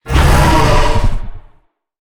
Sfx_creature_hiddencroc_flinch_02.ogg